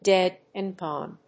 アクセントdéad and góne